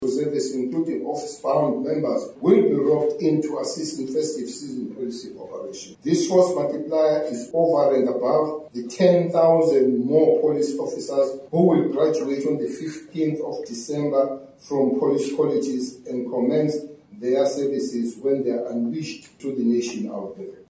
Cele het ook ‘n sterk waarskuwing gerig aan misdadigers en diegene wat beplan om die wet te oortree, en die polisie opdrag gegee om teen elke vorm van misdaad op te tree: